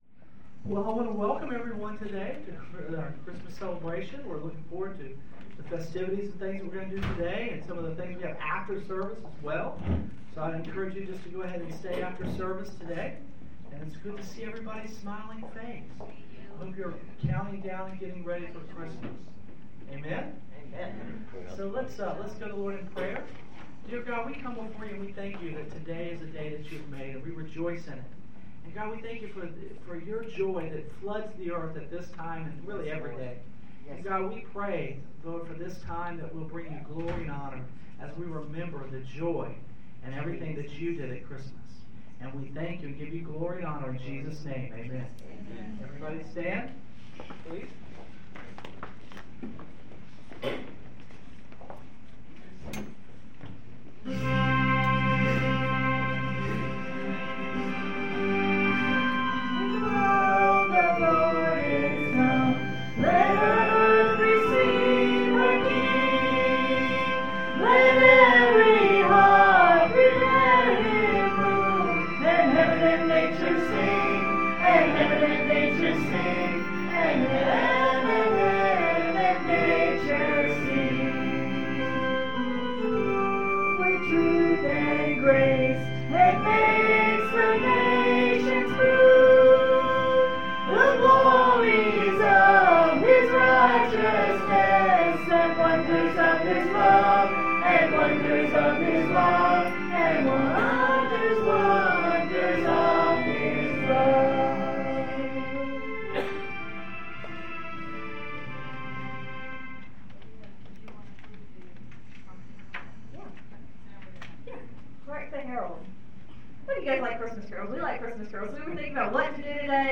This is the Christmas program and service at Southpoint Community Church in Durham, NC, recorded on Sunday December 19, 2010.